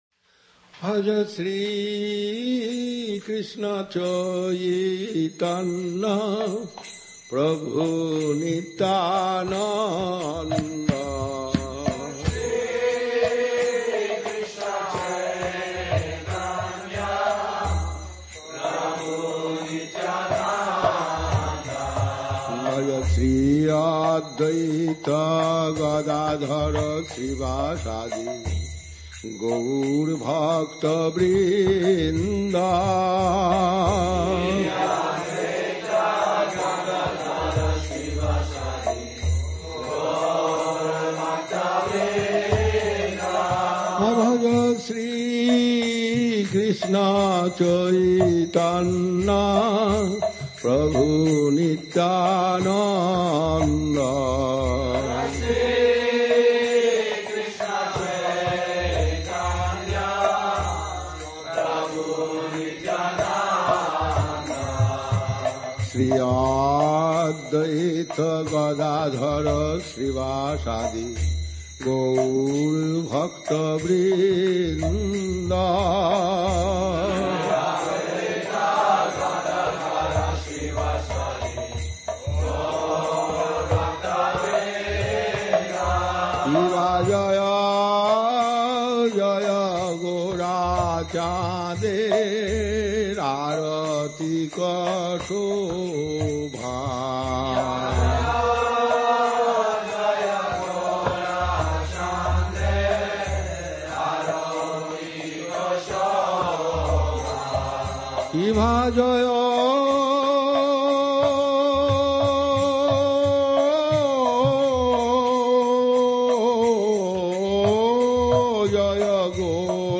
Gupta Govardhan Temple Evening Arati | SCSMath International
The last of the aratis during the day is the arati at Sri Gupta Govardhan temple, where the devotees gather to one more time present themselves before the presiding Deities of Sri Chaitanya Saraswat Math and try to serve and glorify the Lordships of our Gurudevas.